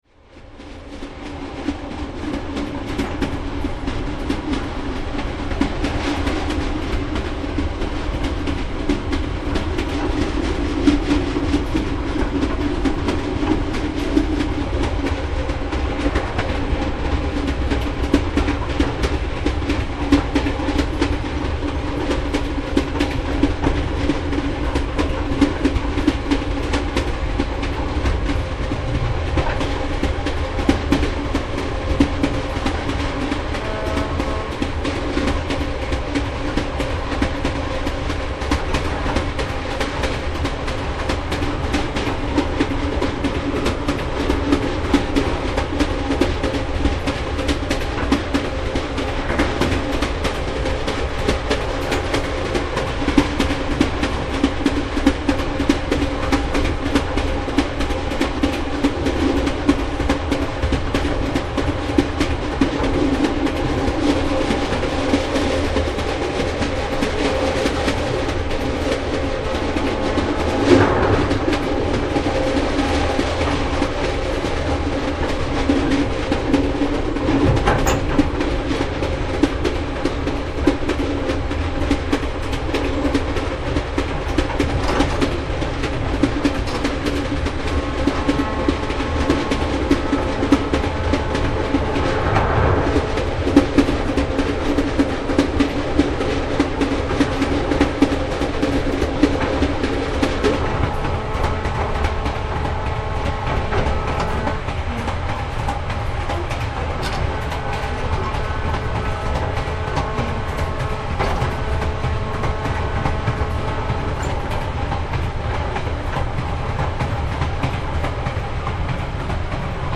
バイノーラル録音とは、簡単に言えばヘッドホン向けに処理された音声だそうです。
列車の音
Train.mp3